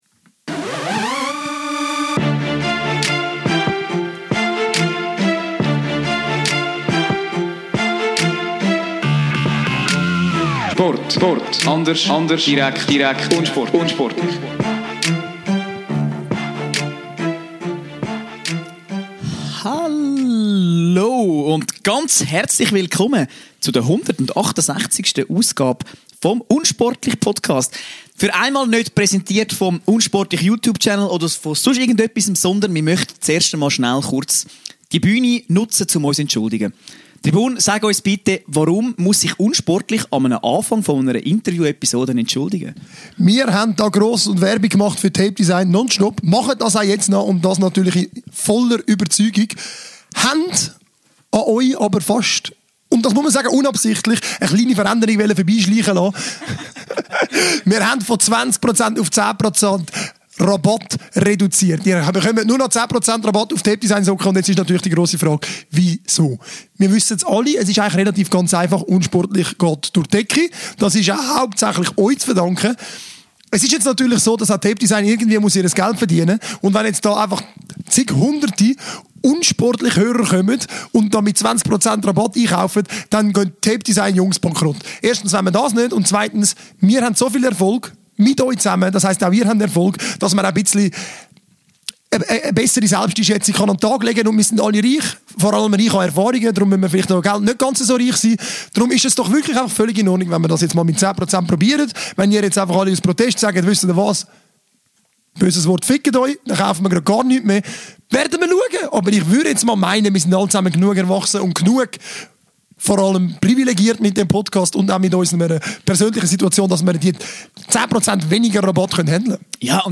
Die Schweizer Nati verlor das erste EM-Spiel gegen die Schweden (!!!). Nach dem Interview wissen wir, der Fehler war riesig.